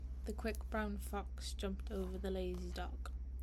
You can hear me loud and clear
The beamforming microphones on the webcam help to isolate the sound coming from the speaker on the call. As you can hear in this recording, my voice is isolated from the other sounds in the office, like typing and quiet chatter.
I used the webcam with the Focus setting since I’m usually in the office when I take calls, and I want my voice to be isolated.
Insta360 Link 2 Pro - Microphone test.mp3